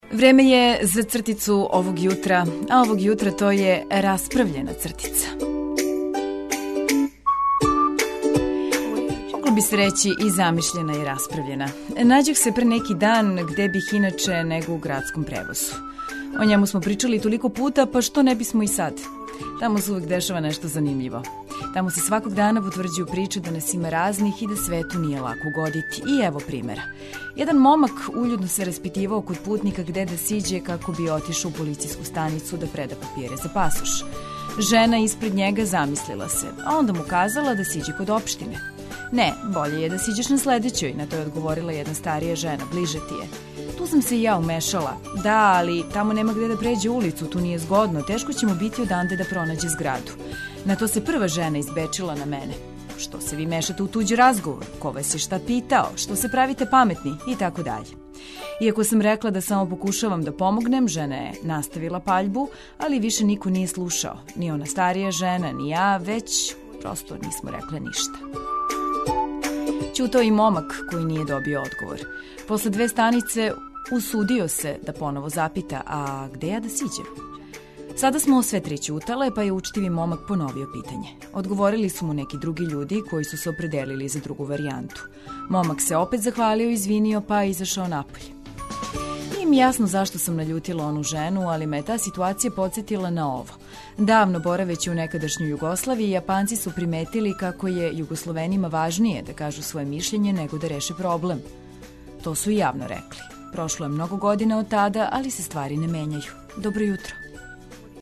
Јутро на Велики петак уз јутарњи програм Двестадвојке.